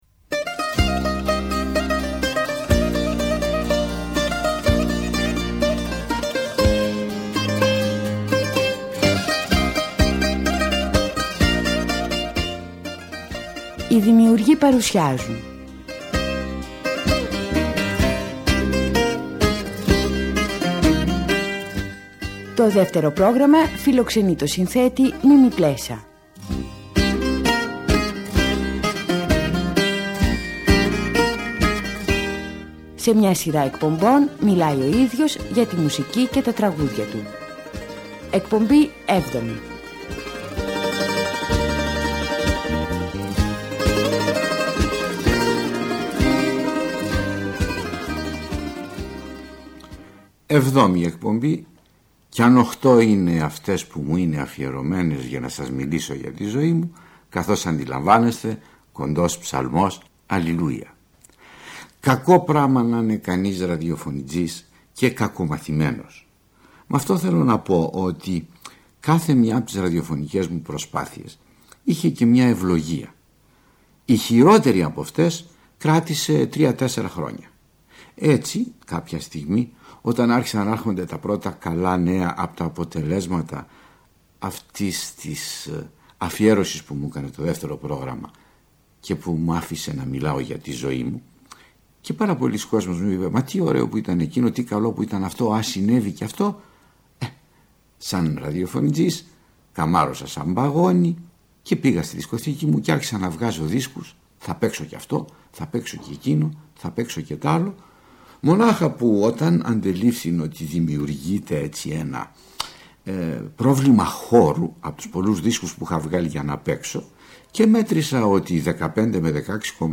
Στις εκπομπές αυτές, ο μεγάλος συνθέτης αυτοβιογραφείται, χωρίζοντας την έως τότε πορεία του στη μουσική, σε είδη και περιόδους, διανθίζοντας τις αφηγήσεις του με γνωστά τραγούδια, αλλά και με σπάνια ηχητικά ντοκουμέντα.